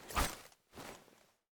axe_swing_var2.ogg